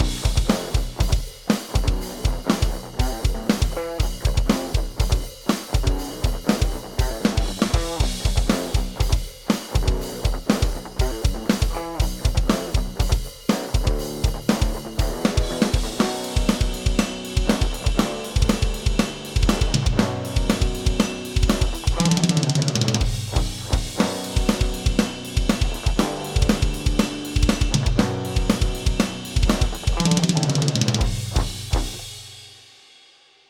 Tubescreamer
Il mio consiglio è di impostare il Drive a metà o appena al di sotto, e il livello abbastanza alto in modo da entrare nell'amplificatore con un segnale già bello saturo e medioso, in modo da dover dare poco gain e far invece lavorare bene lo stadio finale.
Gtrs_TS_NoAmp.mp3